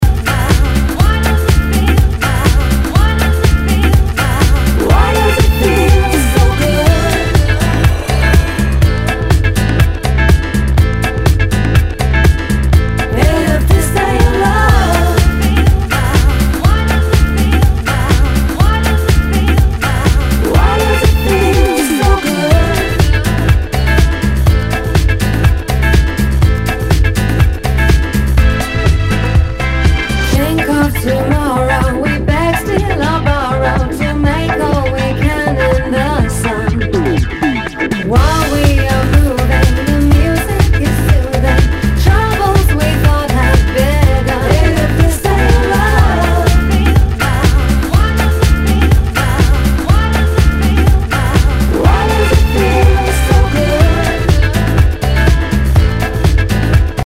HOUSE/TECHNO/ELECTRO
ナイス！ヴォーカル・ハウス！
全体にチリノイズが入ります